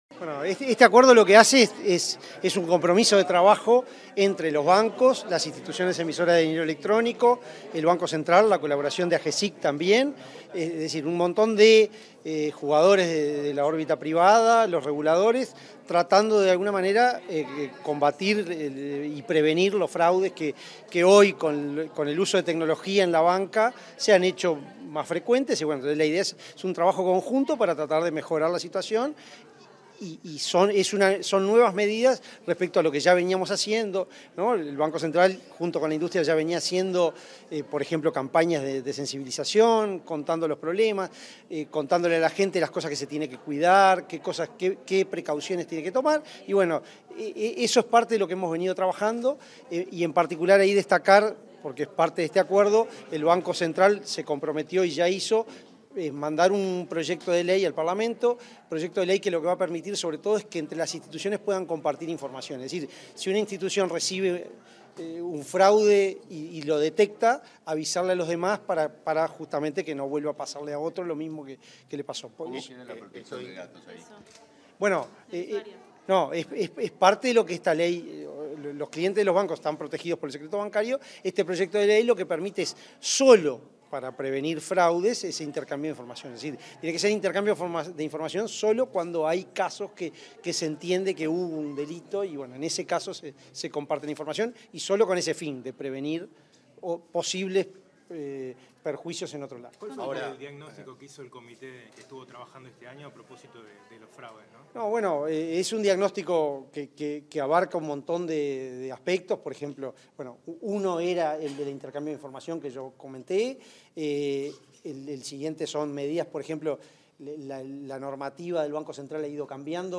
Declaraciones a la prensa del presidente del BCU, Diego Labat